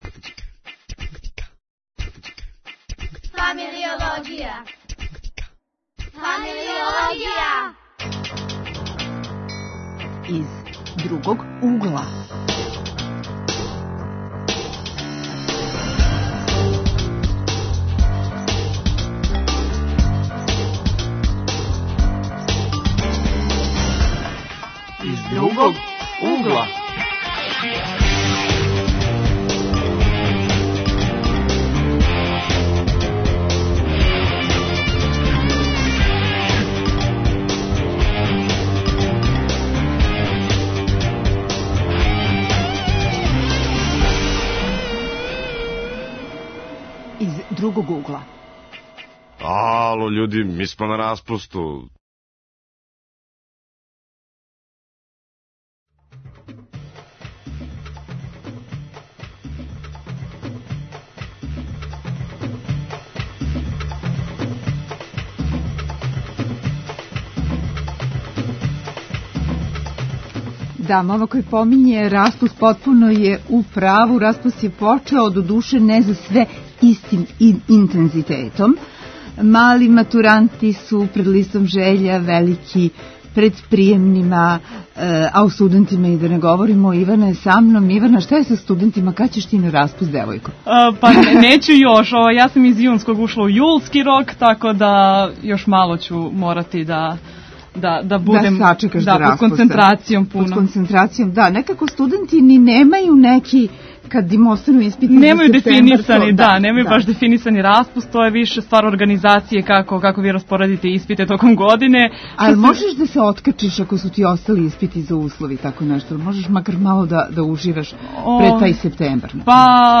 У госте нам долазе активни средњошколци и студенти који предлажу праве ствари.